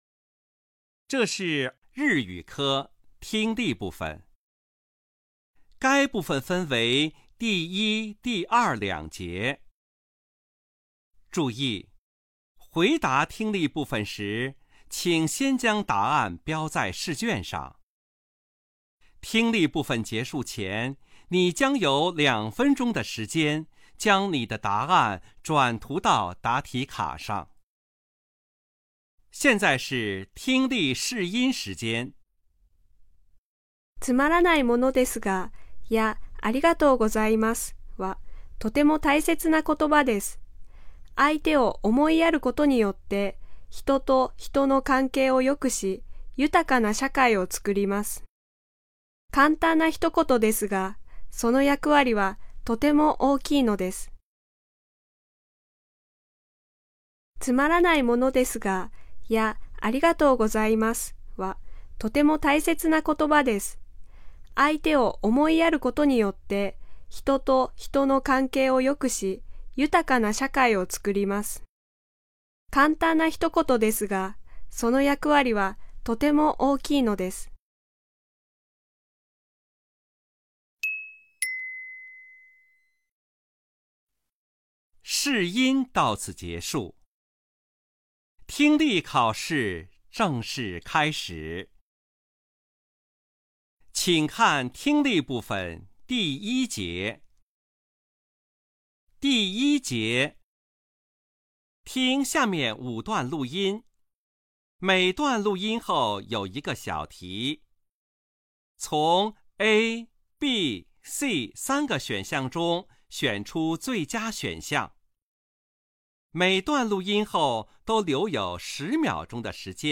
2025年新高考综合改革适应性演练日语听力.mp3